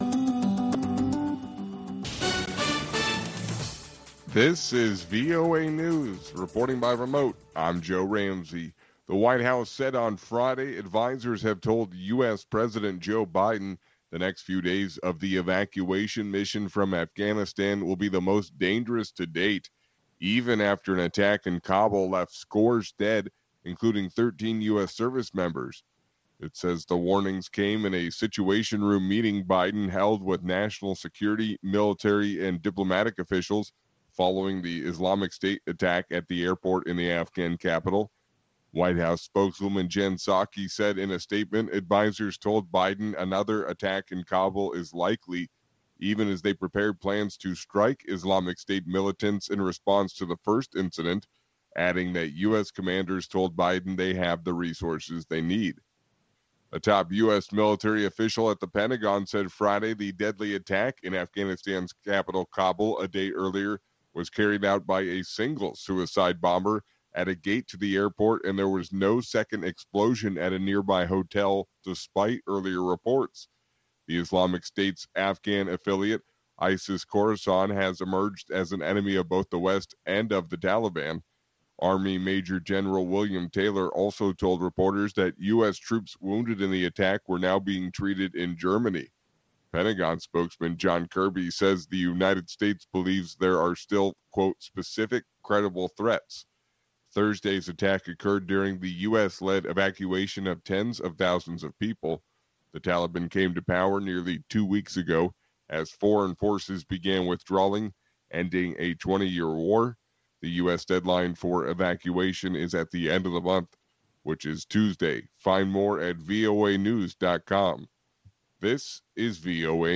We bring you reports from our correspondents and interviews with newsmakers from across the world.